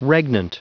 Prononciation du mot regnant en anglais (fichier audio)
Prononciation du mot : regnant